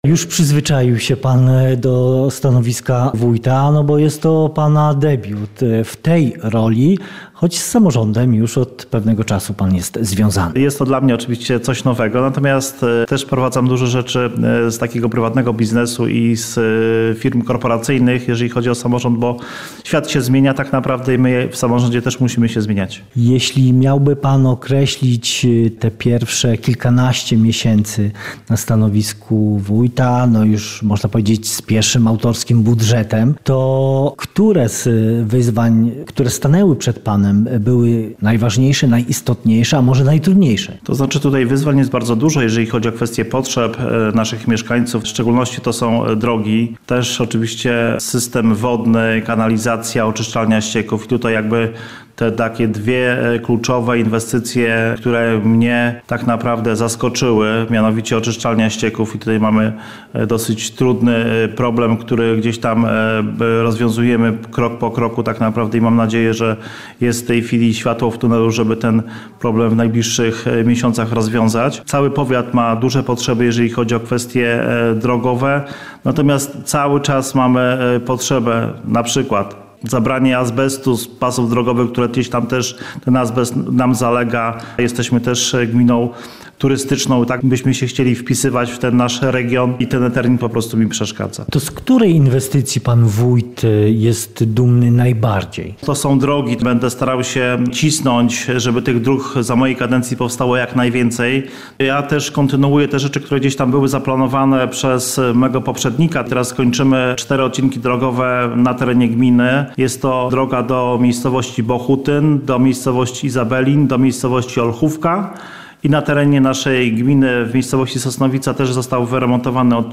O remontach dróg, wykorzystaniu walorów przyrodniczych i historycznych oraz planach inwestycyjnych na 2026 rok Mariusz Hołowieniec, wójt gminy Sosnowica opowiada w rozmowie